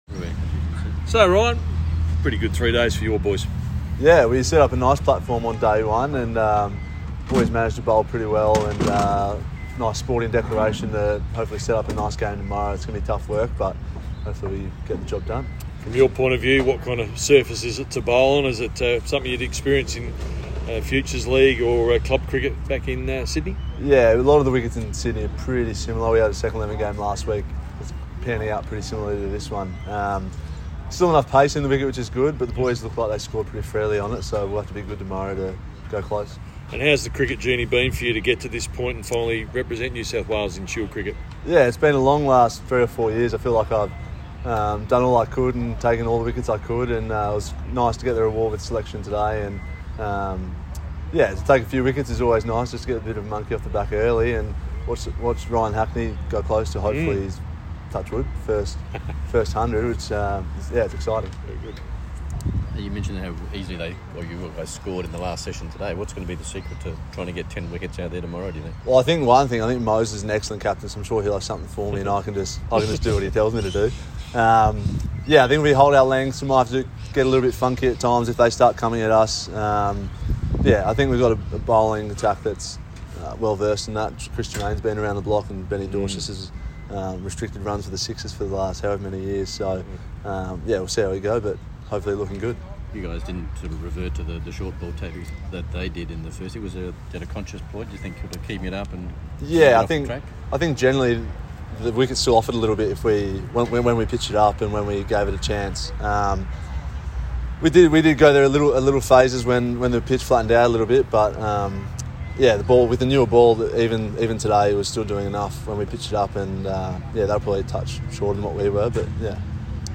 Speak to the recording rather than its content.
speaking at stumps on day 3